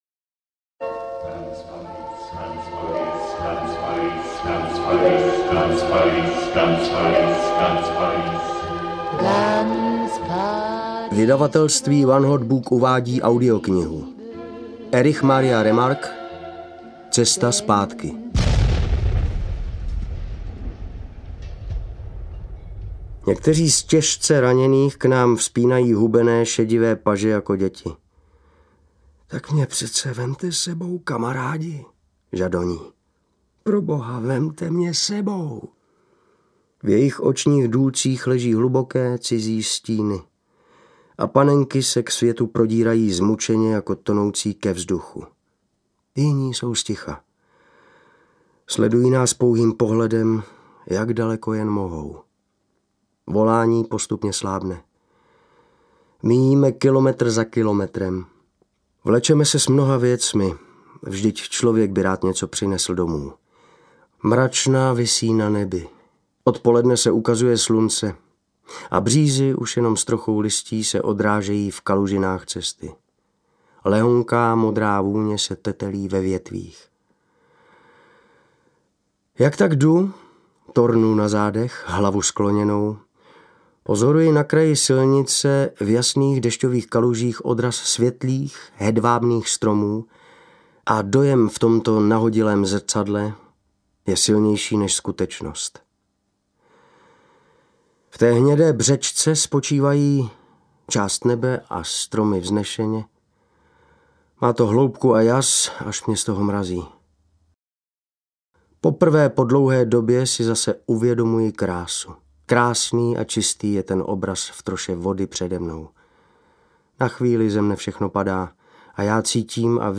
Cesta zpátky audiokniha
Ukázka z knihy